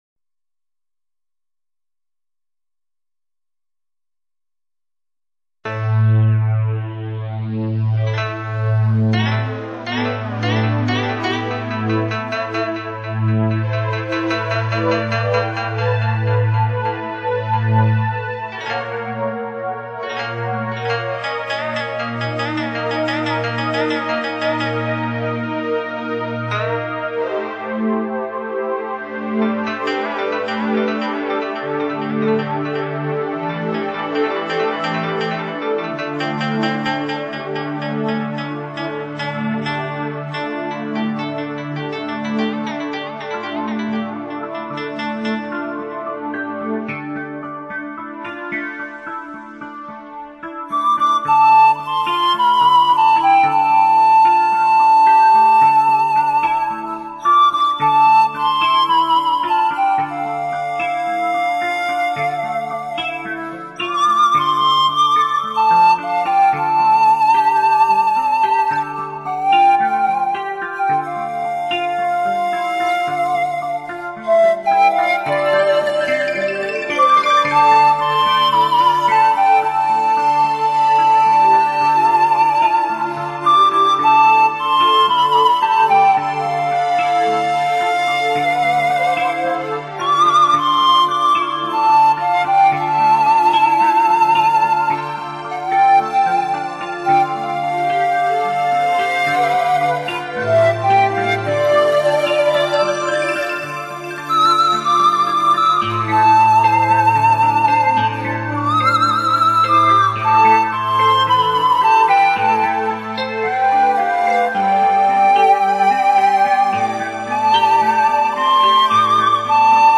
排笛音域宽广，音区变化大，音色丰富。
排 箫 简 述 排箫又叫排笛、潘笛，是西洋乐器中最有魅力的乐器之一。